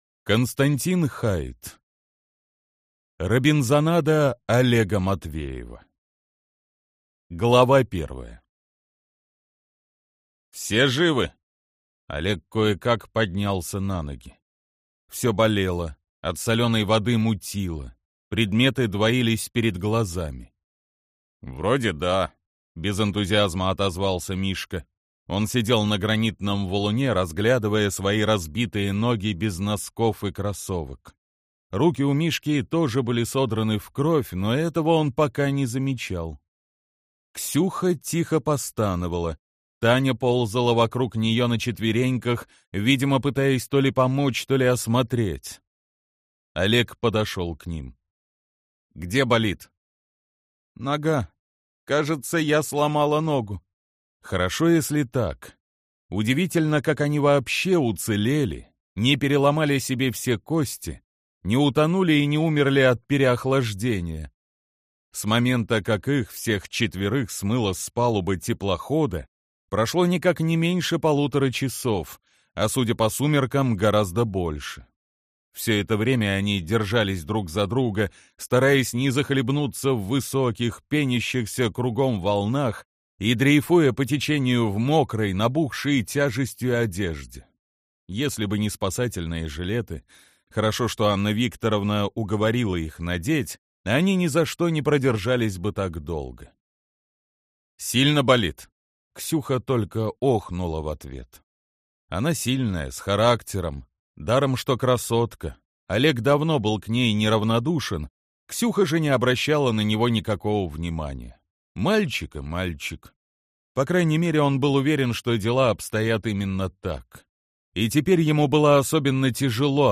Аудиокнига Робинзонада Олега Матвеева | Библиотека аудиокниг